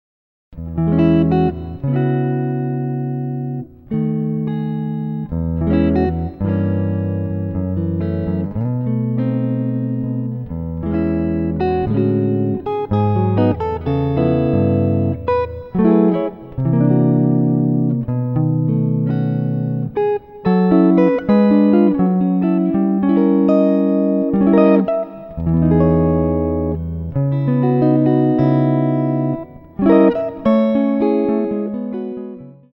solo guitar arrangements